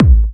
VEC3 Bassdrums Trance 44.wav